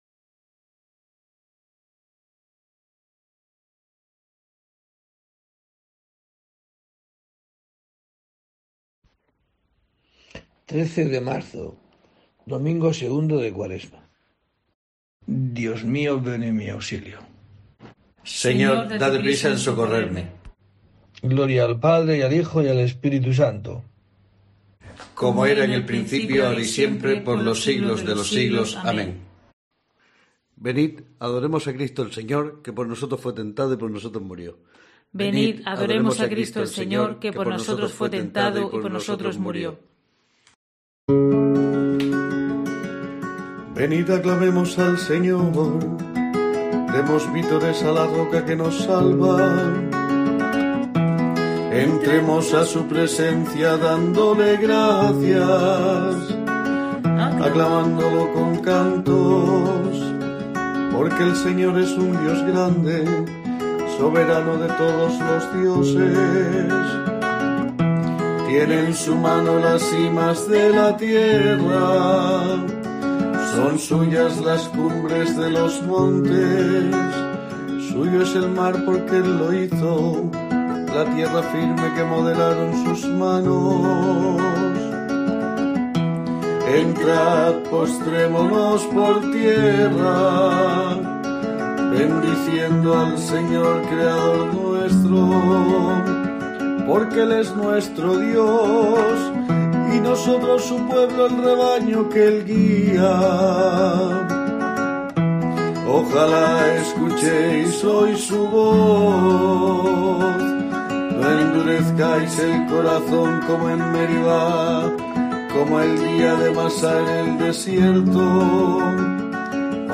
13 de marzo: COPE te trae el rezo diario de los Laudes para acompañarte